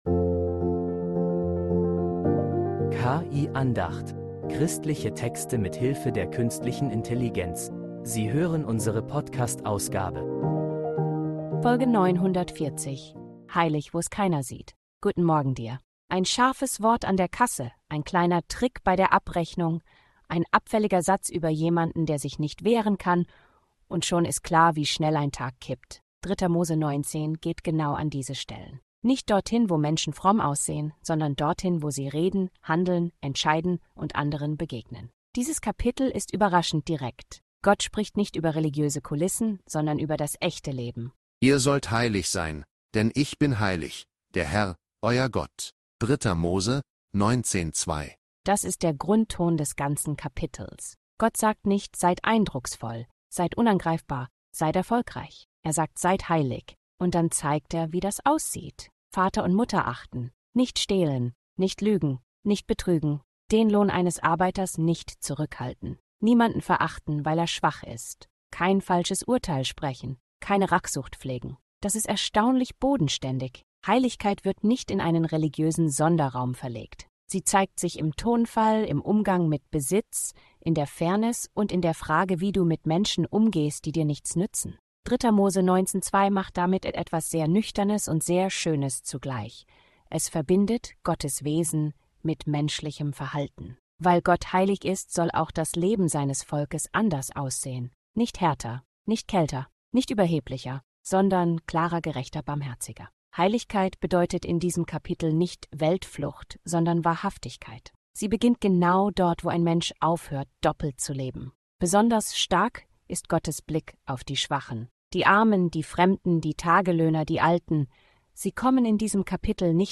Eine Andacht zu 3. Mose 19